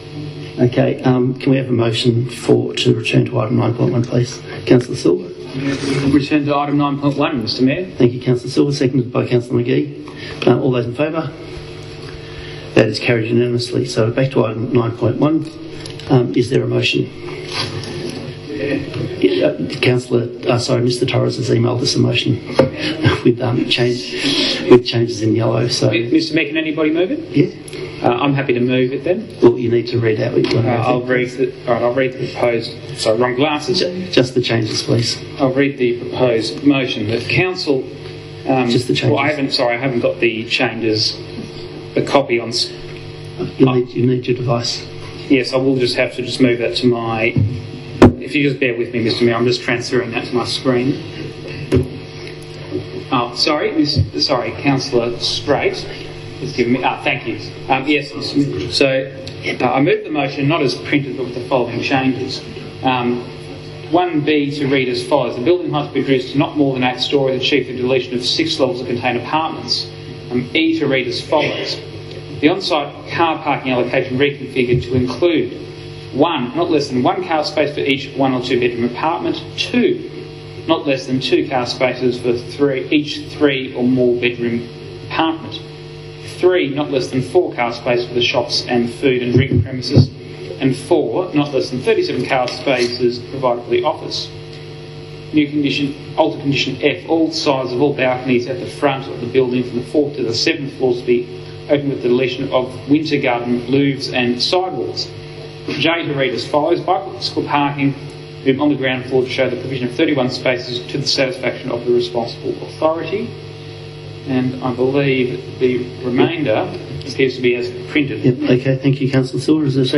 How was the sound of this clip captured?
Tonight’s council meeting resembled a three ring circus and revealed once again to what extent decisions are made behind closed doors in Glen Eira.